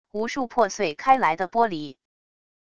无数破碎开来的玻璃wav音频